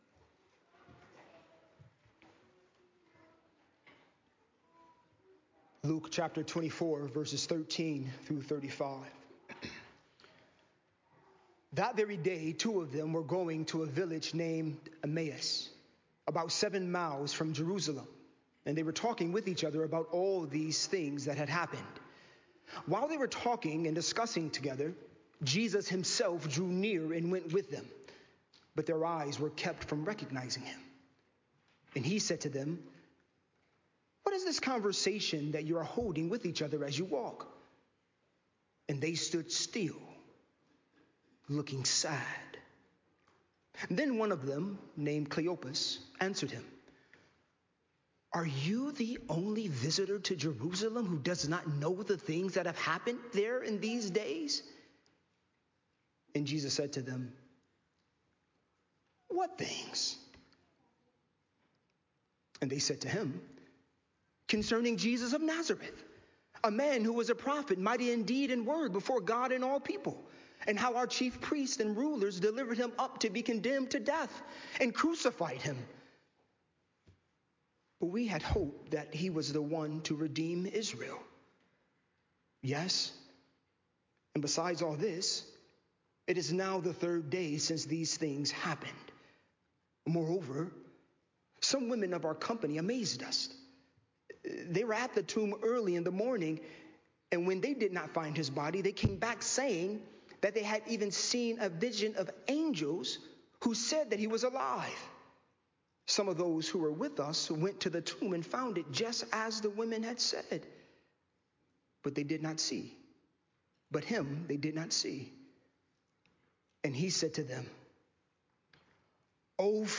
Easter Message